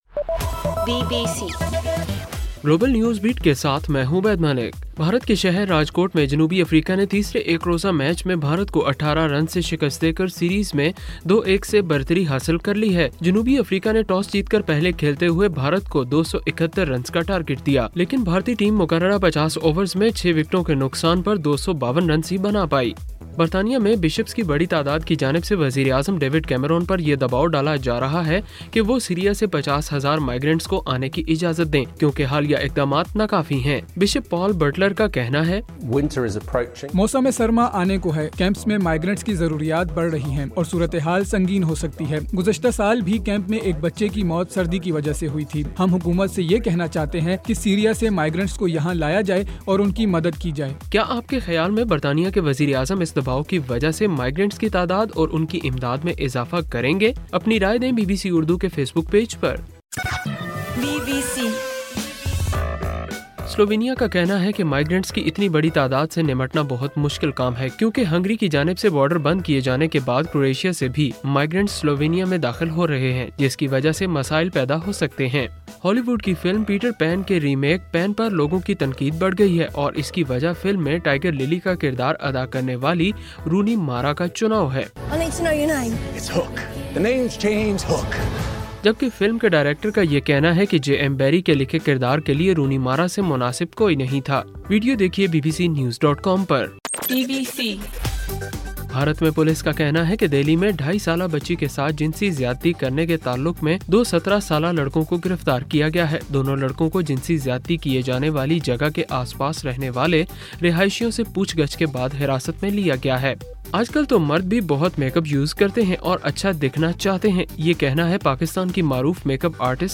اکتوبر 19:صبح 1 بجے کا گلوبل نیوز بیٹ بُلیٹن